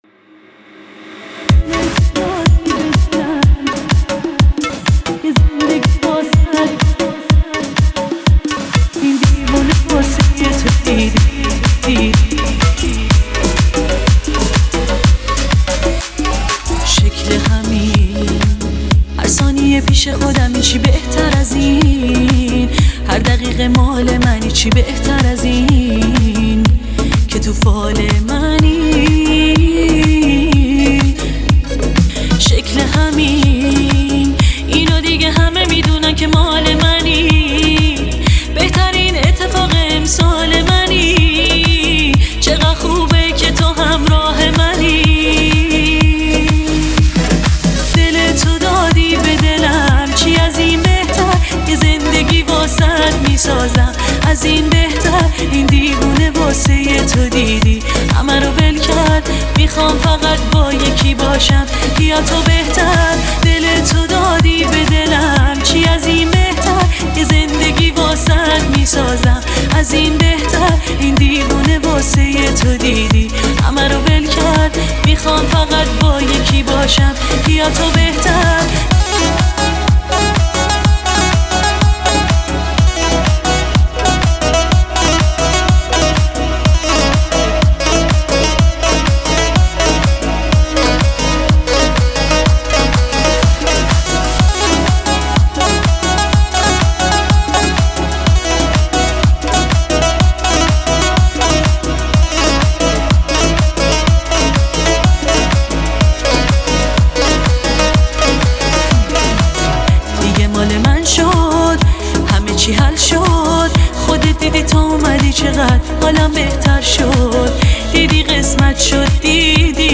آهنگ شاد ایرانی